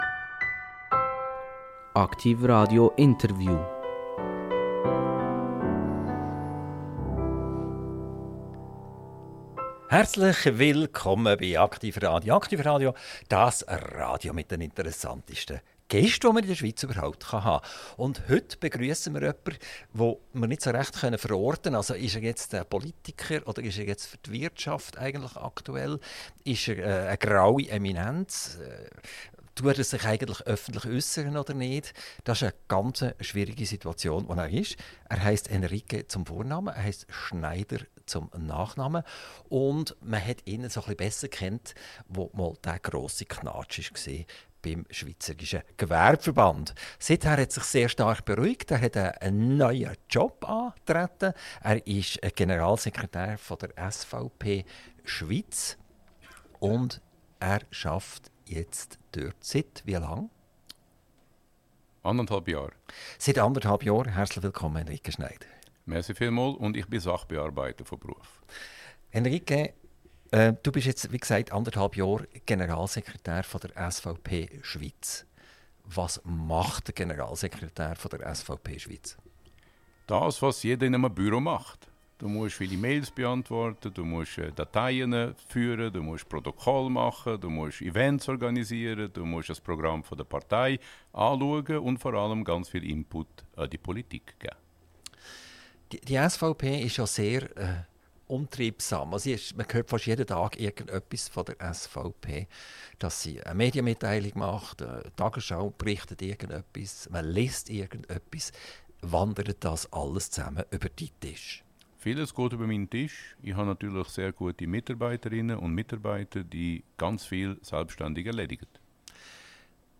INTERVIEW - Henrique Schneider - 28.10.2025 ~ AKTIV RADIO Podcast